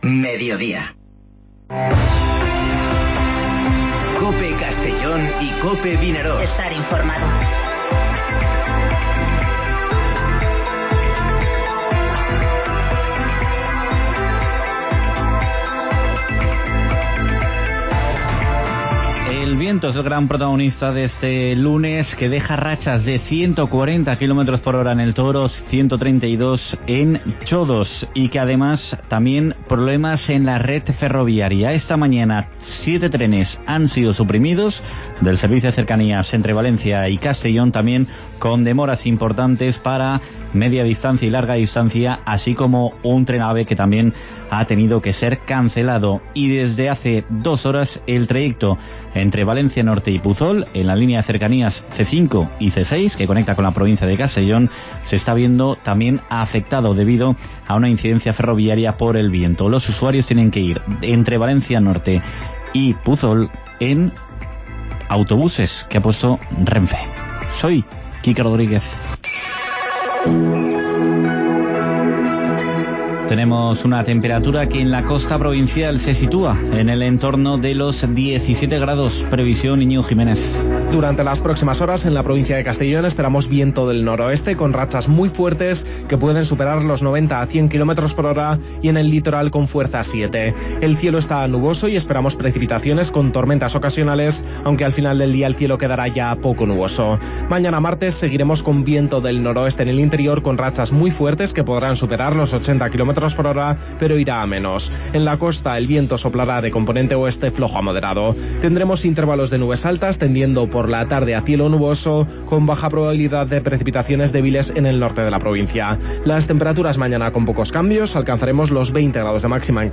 Informativo Mediodía COPE en la provincia de Castellón (02/03/2020)